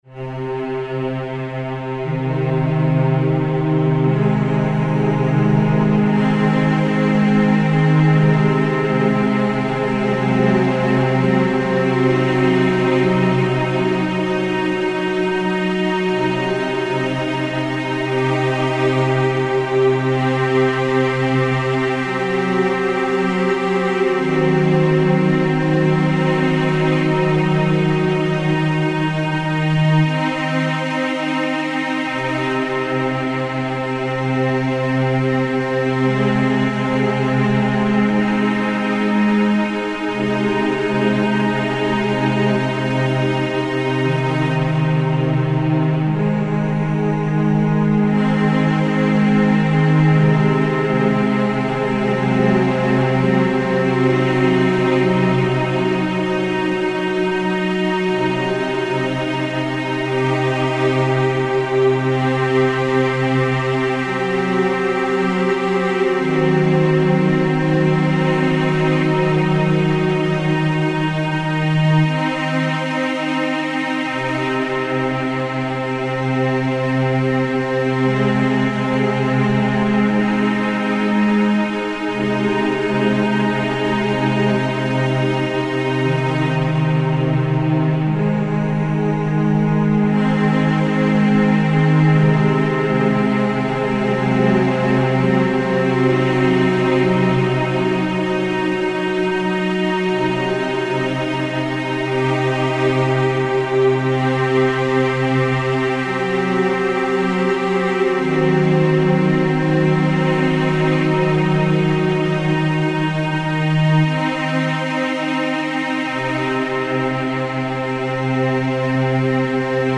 Música electrónica
ambiental
melodía
sintetizador